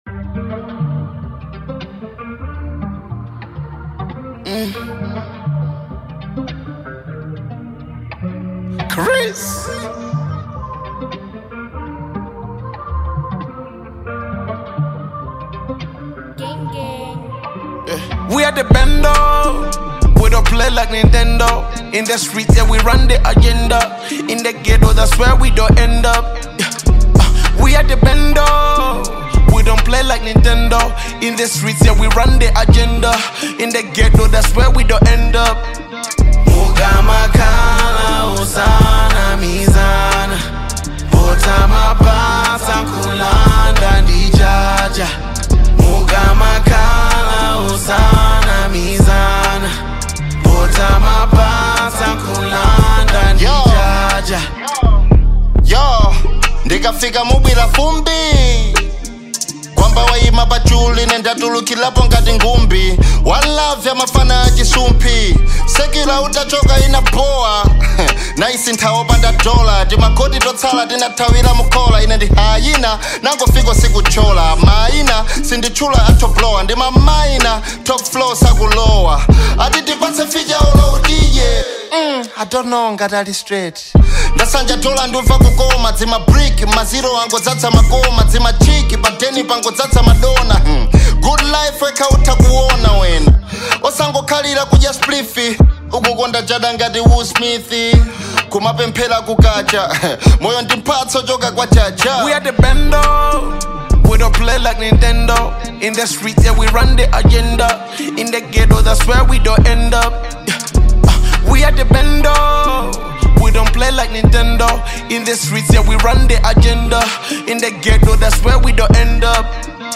Genre : Hiphop/Rap